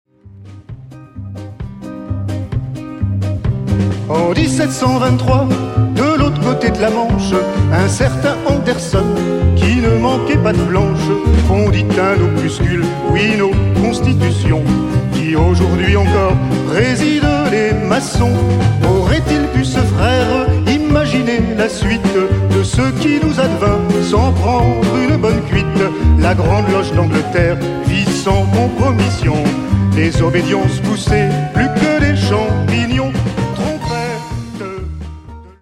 Une chanson du spectacle Les Monologues du Frangin des Loges de la Folie à Bruxelles en 2010.